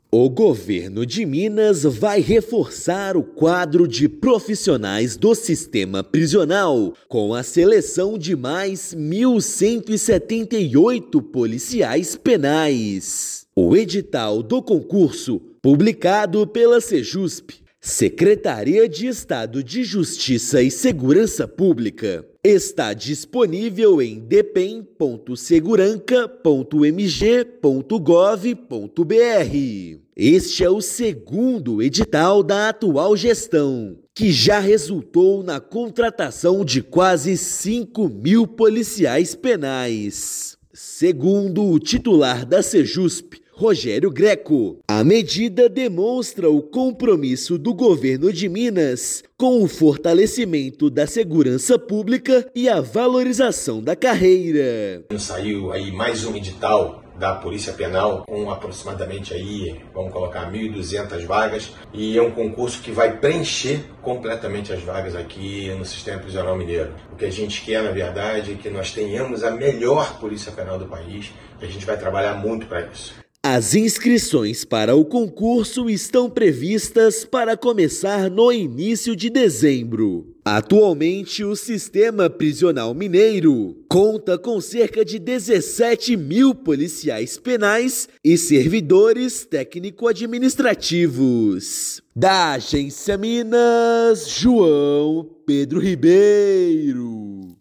[RÁDIO] Novo concurso da segurança pública oferece vaga para mais 1.178 policiais penais
Este é o segundo edital da atual gestão, que já contratou quase 5 mil profissionais para a área; provas serão em janeiro. Ouça matéria de rádio.